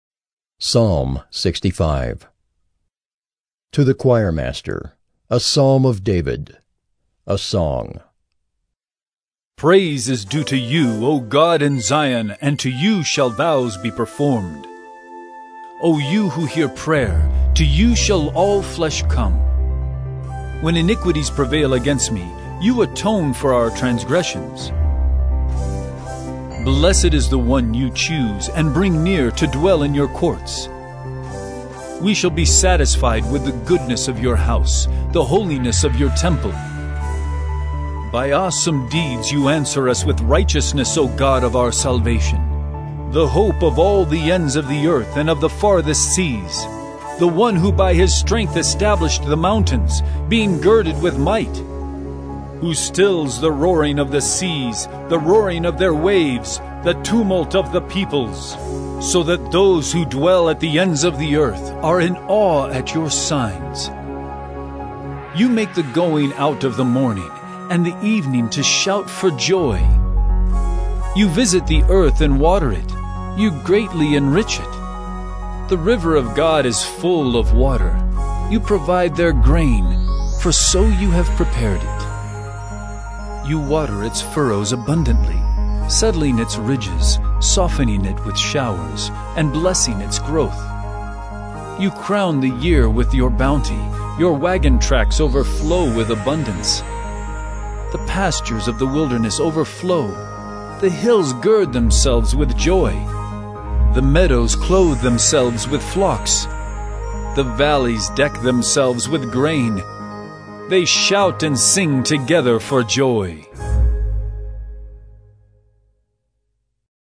“Listening to God” Bible Reading & Devotion: Jun 15, 2020 – Psalms 65 – 萬民福音堂「聽主話」讀經靈修